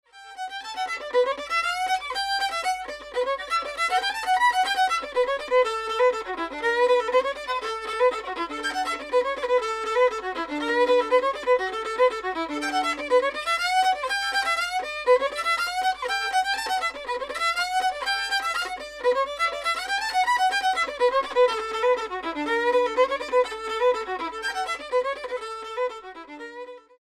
The A and E strings are shown in
This is with a cheap mono computer mic, a £10 Chinese bow, and Corelli Crystal strings. Beware however of amateur violinist.
This fiddle has a strong B natural on the A string..